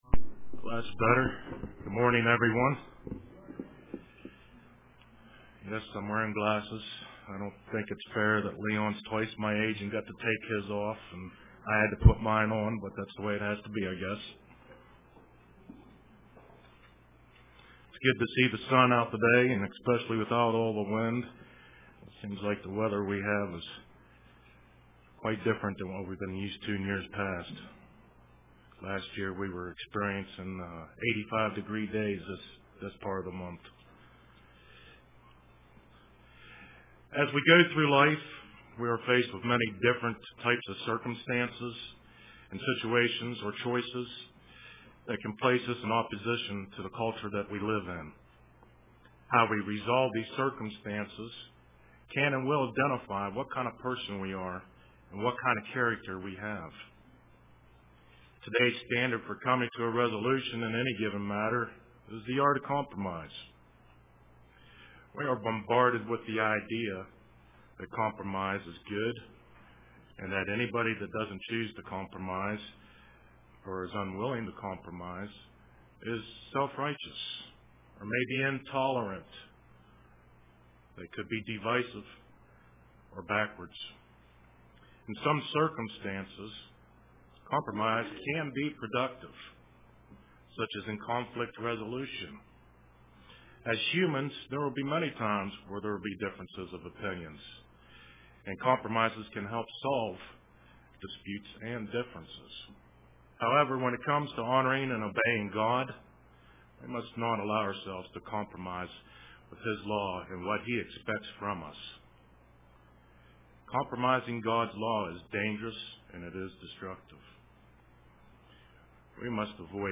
Print The Danger of Compromise UCG Sermon Studying the bible?